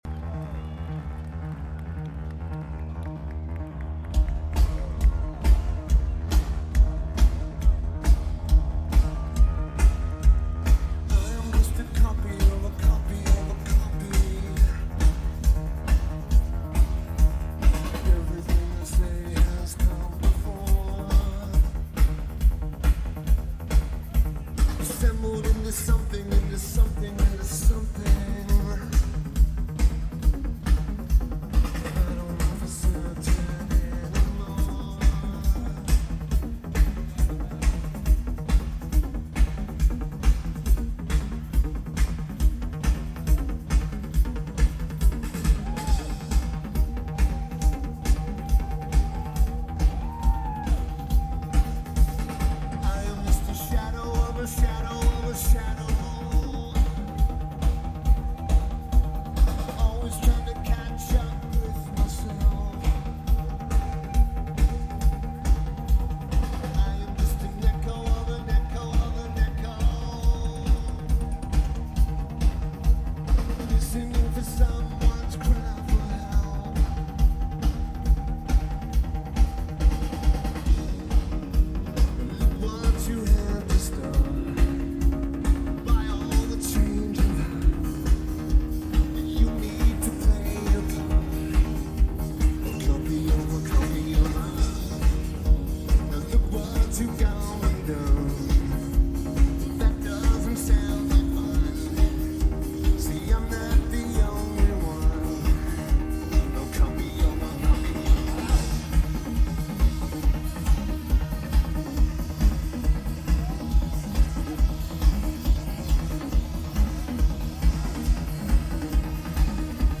Lineage: Audio - AUD (CA14 Card + SP-SPSB-8-MKII + Zoom H1)